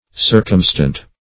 Search Result for " circumstant" : The Collaborative International Dictionary of English v.0.48: circumstant \cir"cum*stant\ (s[~e]r"k[u^]m*st[a^]nt), a. [L. circumstans.